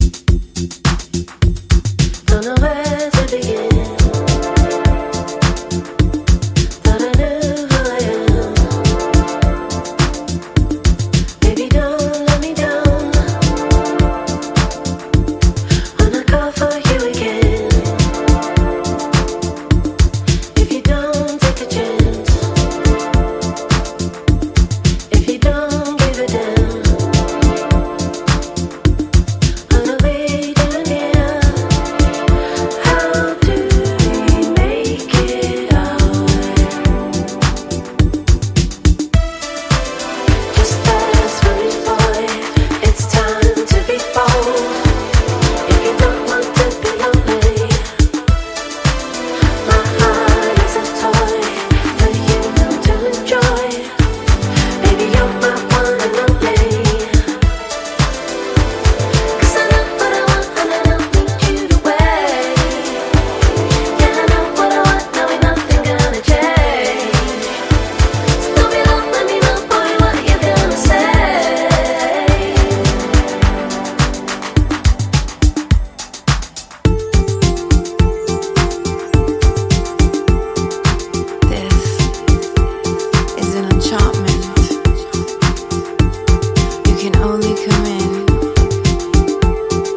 透明感と鮮やかさを纏ったシンセ・ポップを展開していく